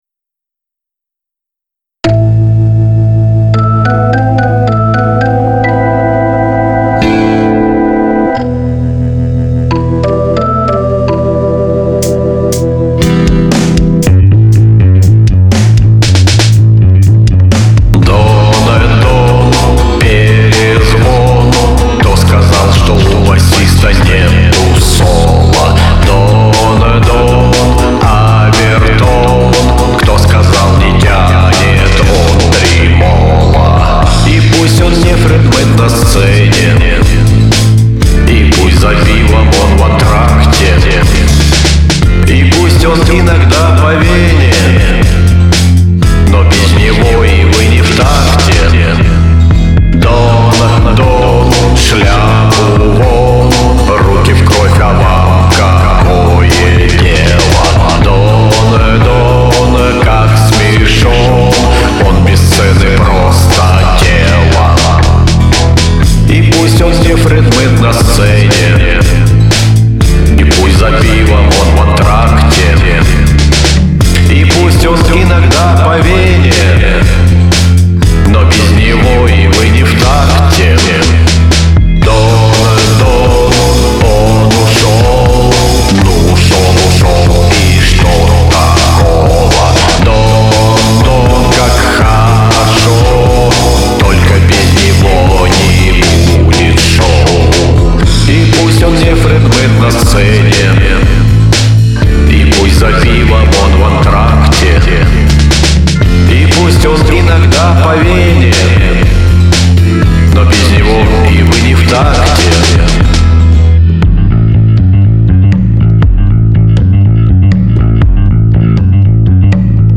Стиль мой- примитивизм.